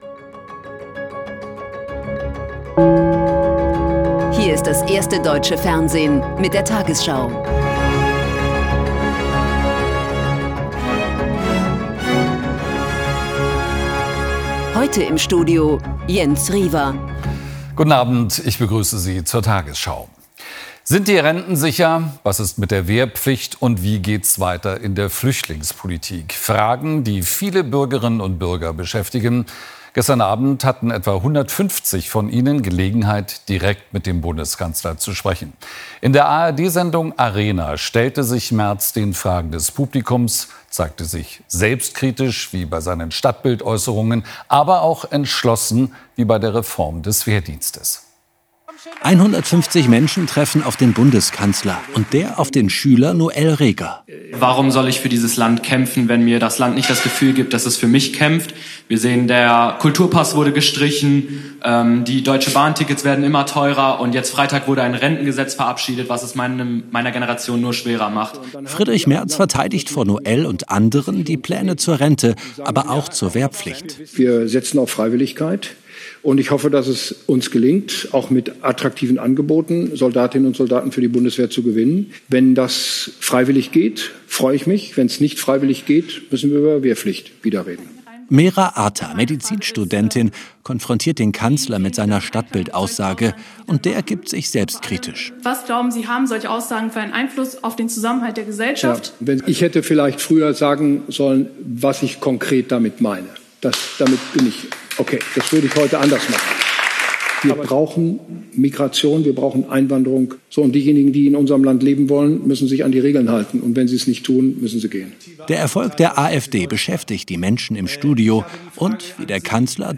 tagesschau 20:00 Uhr, 09.12.2025 ~ tagesschau: Die 20 Uhr Nachrichten (Audio) Podcast